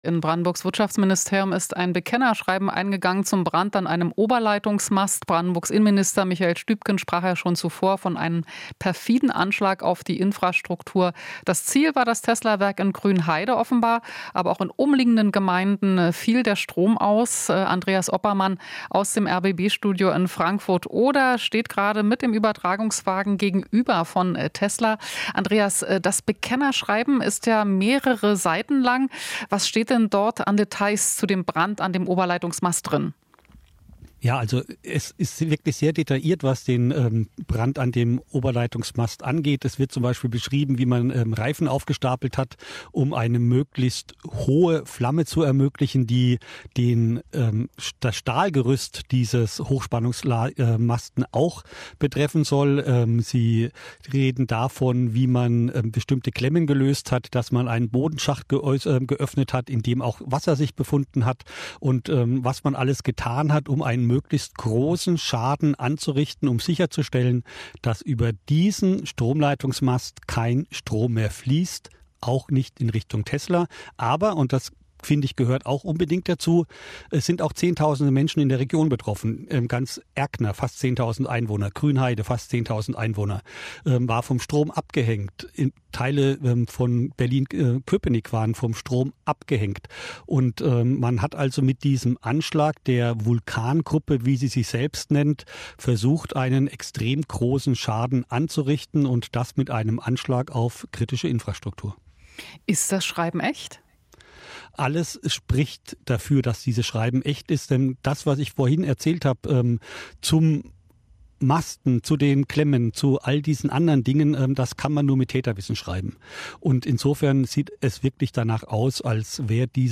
Interview - Stromausfall bei Tesla: "Vulkangruppe" hat sich zu Anschlag bekannt